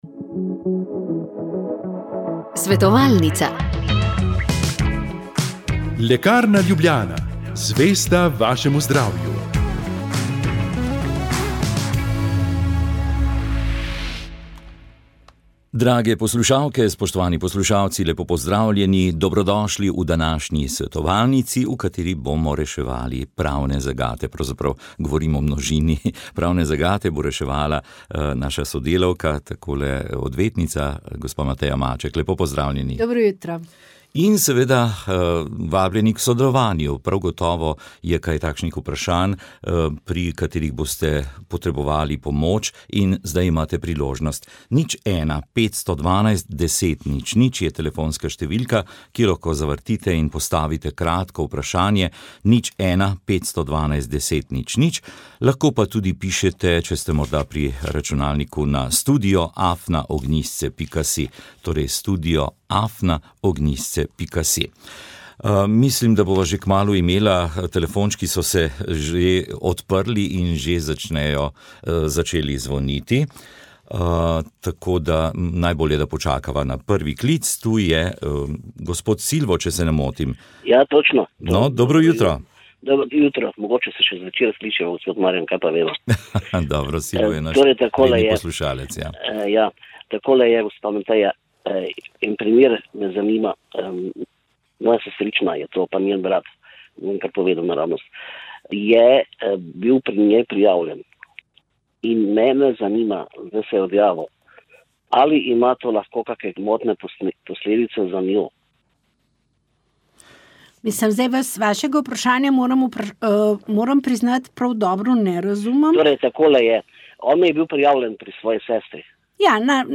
Naš gost je tudi profesor, ki nam je povedal nekaj o svojem literarnem okusu.